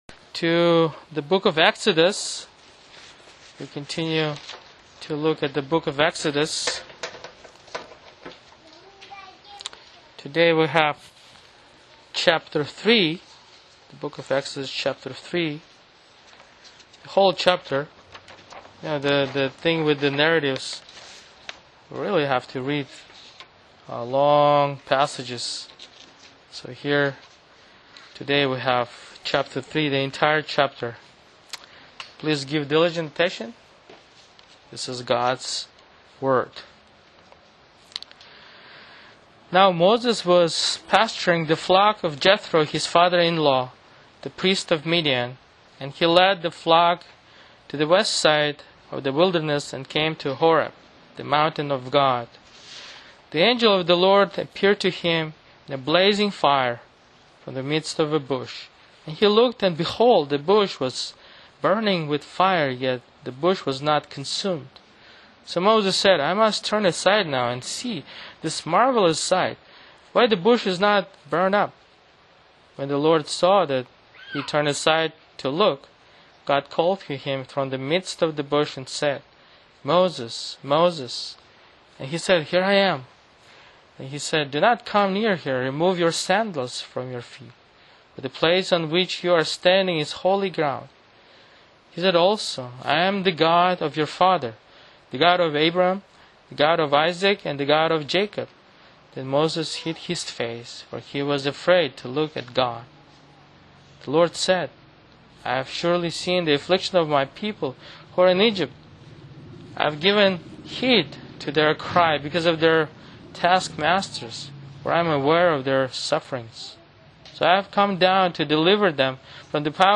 [Sermon] Exodus 3:1-22 (Guest Speaker)